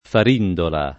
Farindola [ far & ndola ]